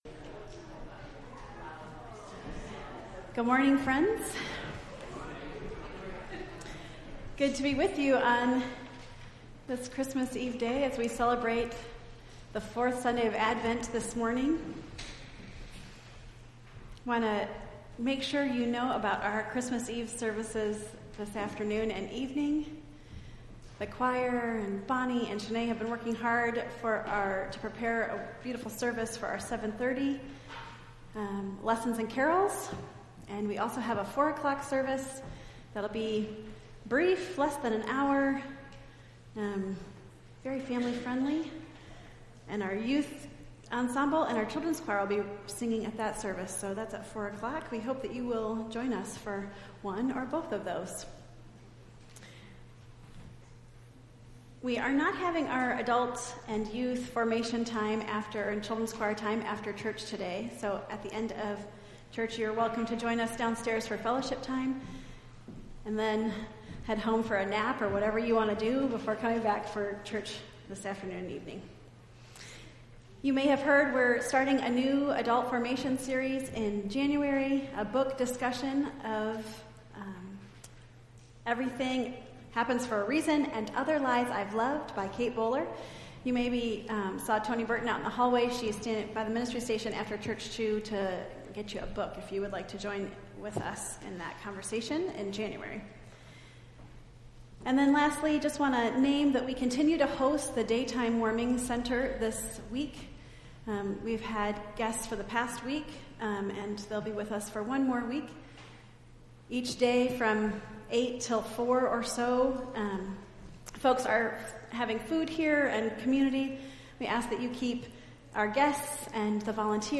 Entire December 24th Service